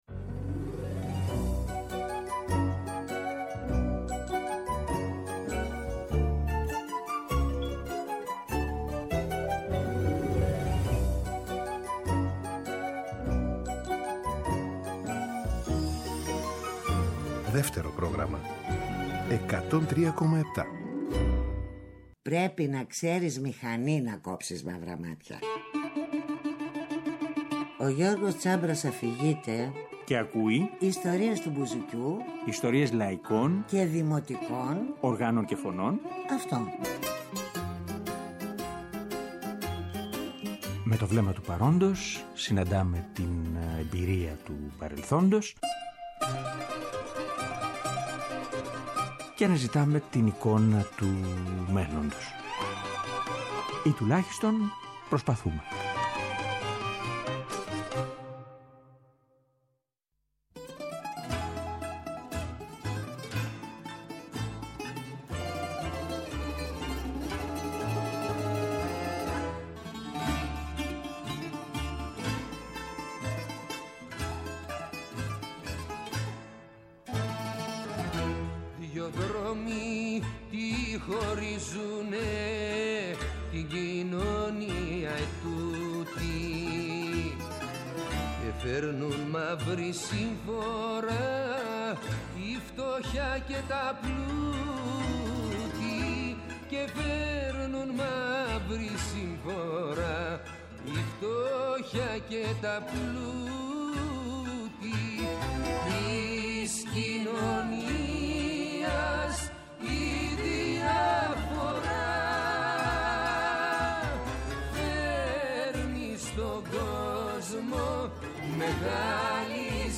Μια συζήτηση